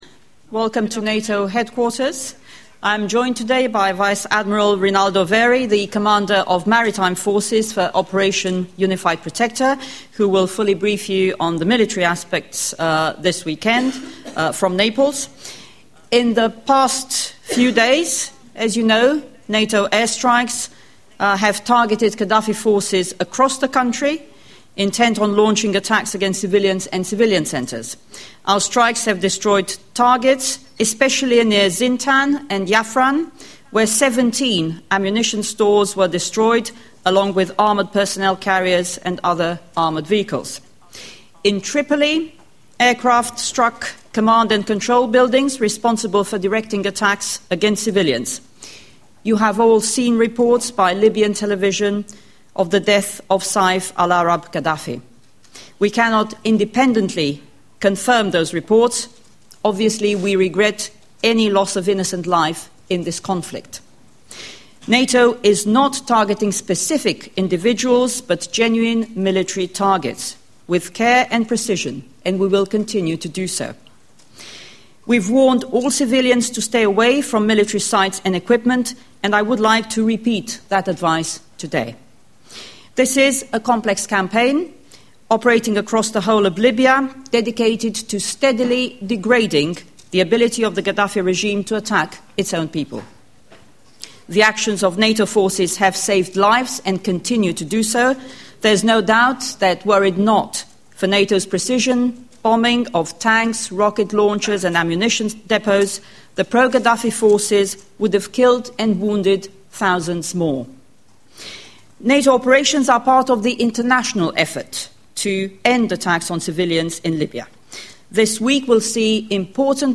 Transcript of the press briefing on Libya